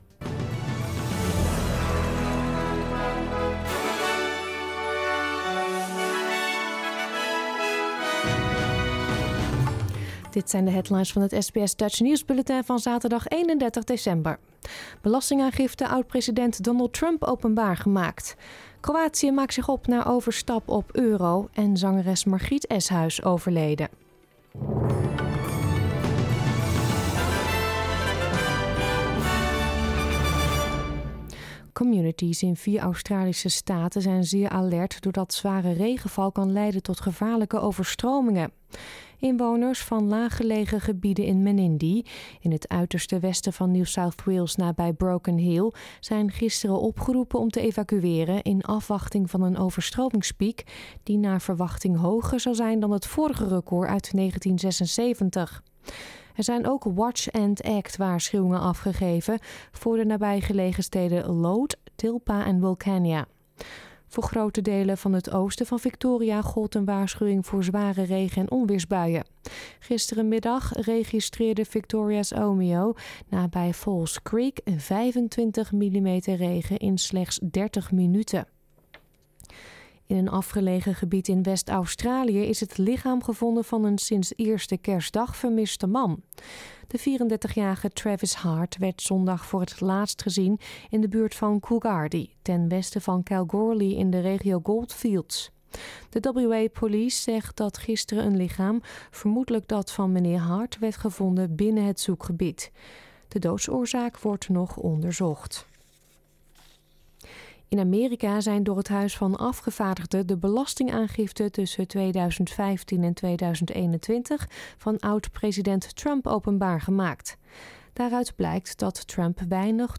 Nederlands / Australisch nieuwsbulletin van zaterdag 31 december 2022